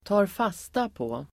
Ladda ner uttalet
Uttal: [²ta:rf'as:tapå:]